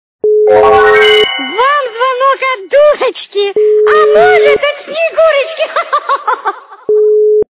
При прослушивании Вам звонок от дурочки. - А может и Снегурочки качество понижено и присутствуют гудки.